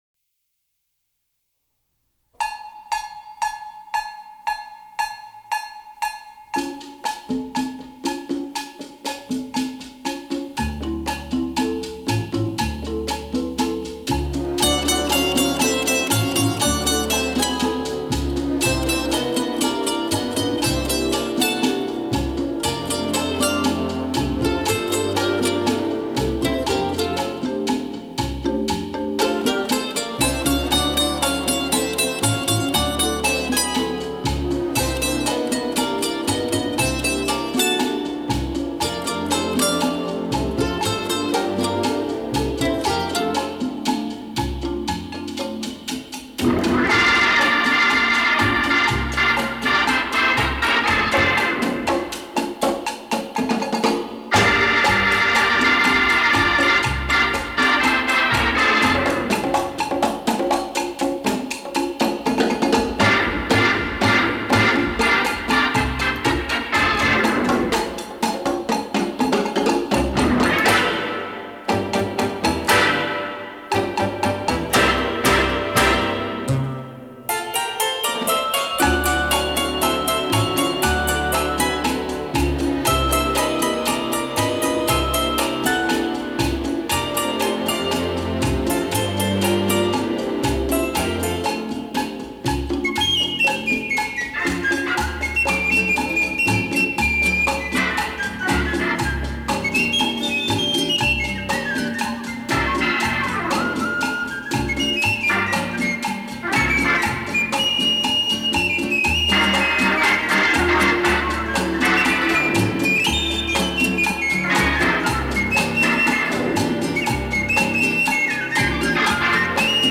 Baritone Saxophone
French Horn
Guitars And Mandolins
Hammond Organ
Percussion
Reeds